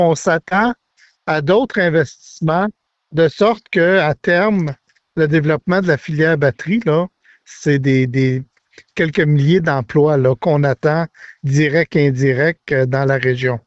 Le député de Nicolet-Bécancour, Donald Martel, en a glissé quelques mots lors d’une entrevue qu’il a accordée.